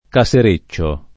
vai all'elenco alfabetico delle voci ingrandisci il carattere 100% rimpicciolisci il carattere stampa invia tramite posta elettronica codividi su Facebook casereccio [ ka S er %©© o ] (meno com. casareccio [ ka S ar %©© o ]) agg.; pl. m. -ci , pl. f. ‑ce